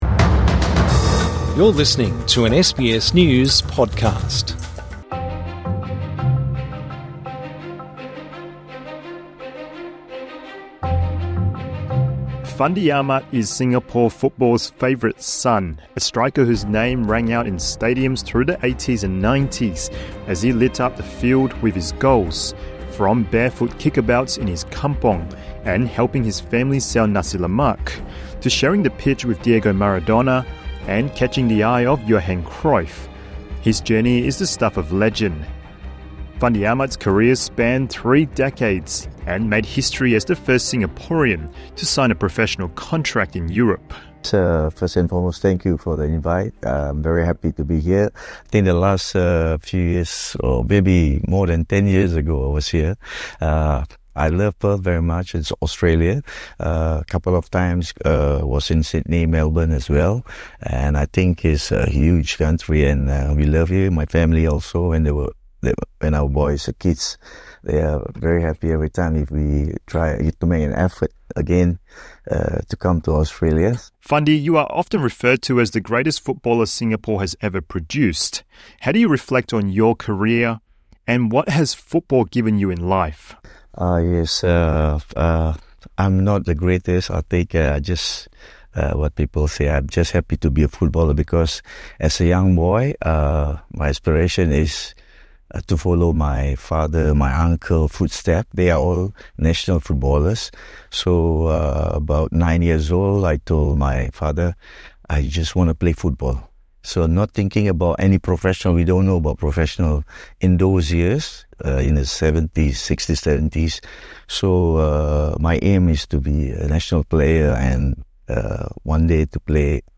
INTERVIEW: Footballer Fandi Ahmad is a Singaporean sporting legend: We find out why